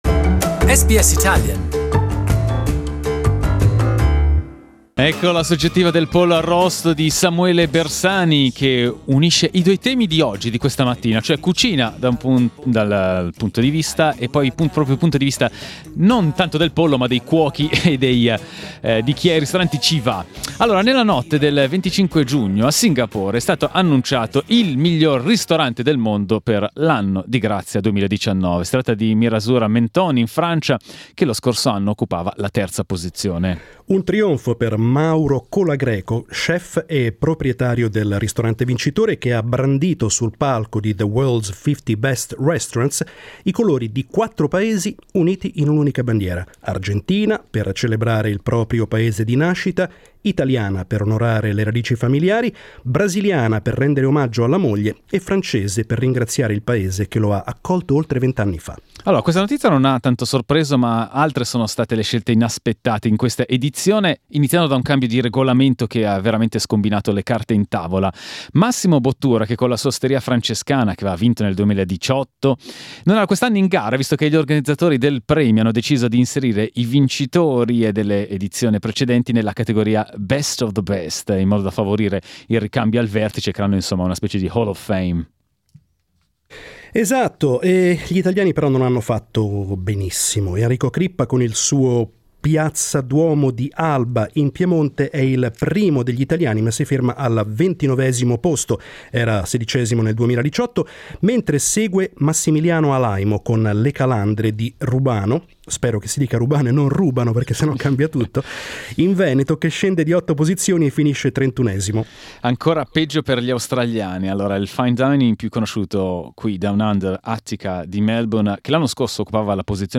The World’s Best Restaurant 2019 list has just been released; our guests and listeners commented the news.